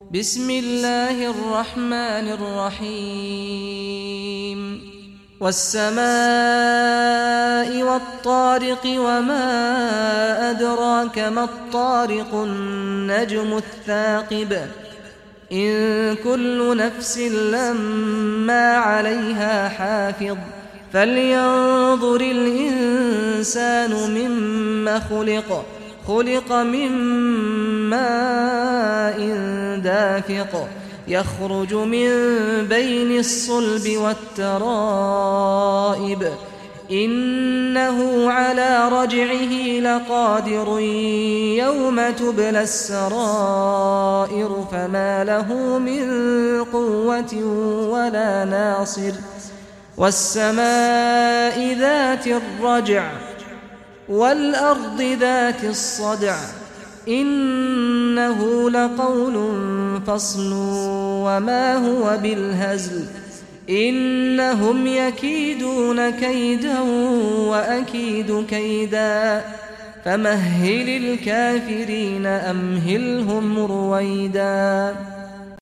Surah Tariq Recitation by Sheikh Saad al Ghamdi
Surah Tariq, listen or play online mp3 tilawat / recitation in Arabic in the beautiful voice of Sheikh Saad al Ghamdi.